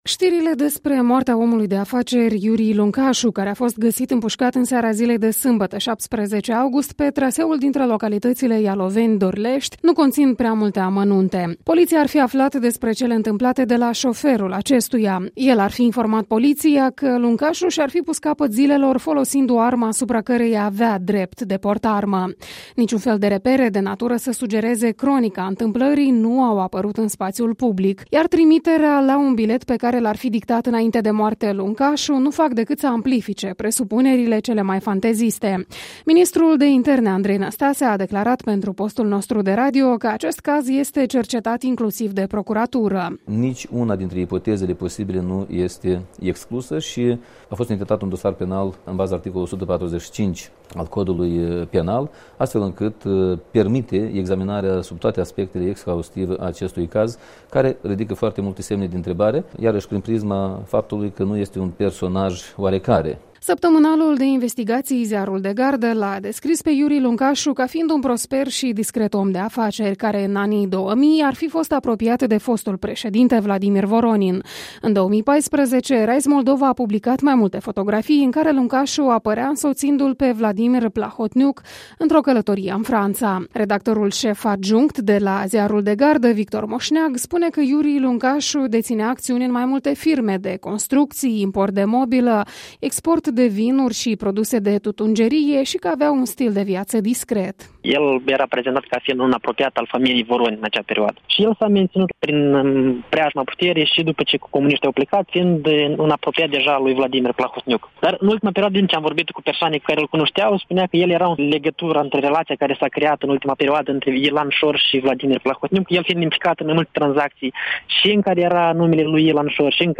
Ministrul de interne, Andrei Năstase, a declarat pentru postul nostru de radio că acest caz este cercetat inclusiv de Procuratură: